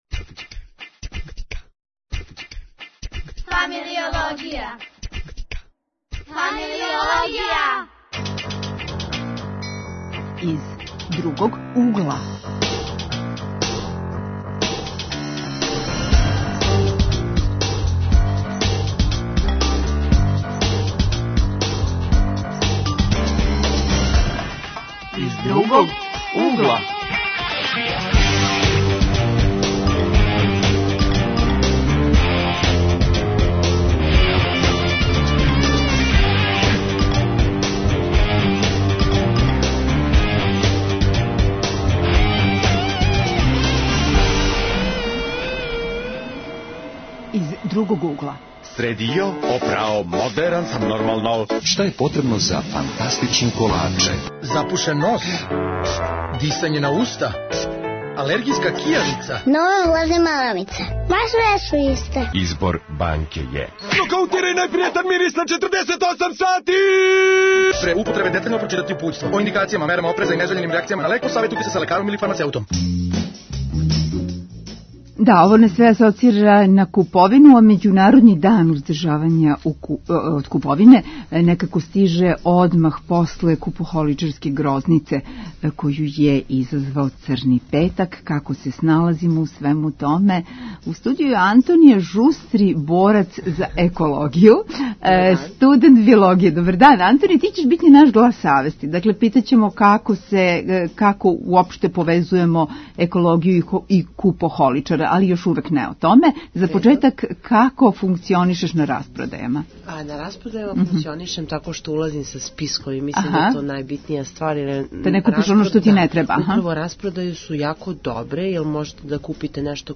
Гости су нам студенти економије и екологије.